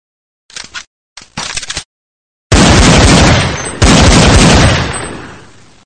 gun_shoot2.mp3